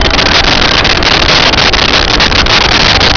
Sfx Pod Chop B Loop
sfx_pod_chop_b_loop.wav